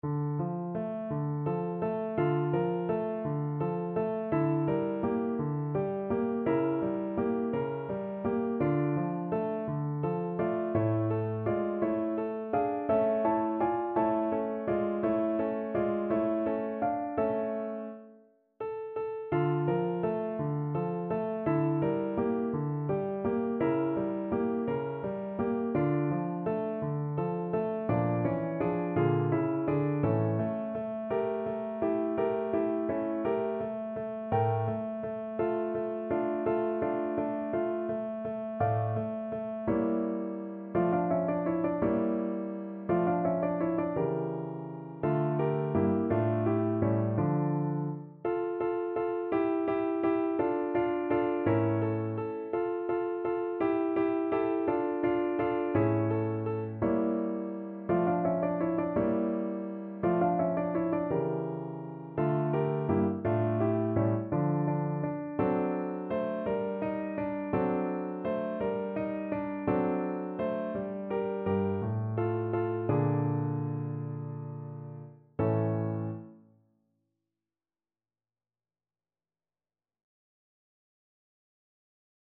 Play (or use space bar on your keyboard) Pause Music Playalong - Piano Accompaniment Playalong Band Accompaniment not yet available transpose reset tempo print settings full screen
6/8 (View more 6/8 Music)
. = 56 Andante
D minor (Sounding Pitch) (View more D minor Music for Trombone )
Classical (View more Classical Trombone Music)